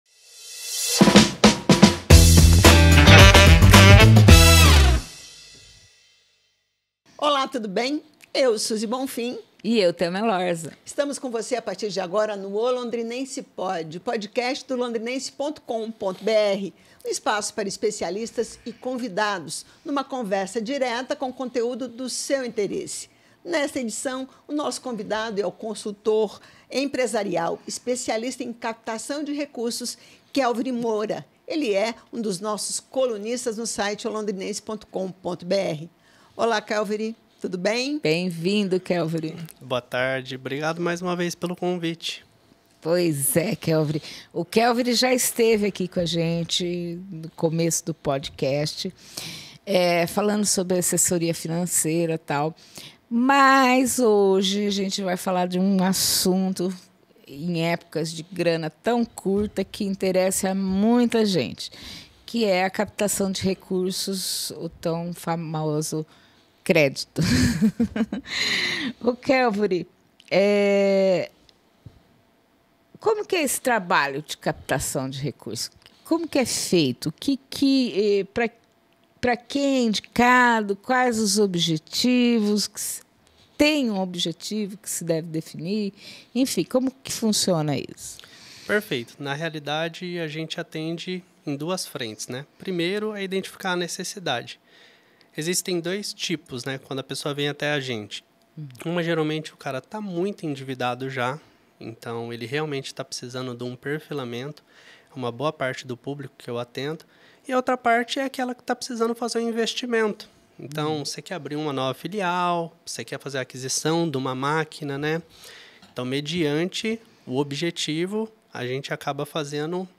Neste episódio 18 conversamos com a especialista em captação de recursos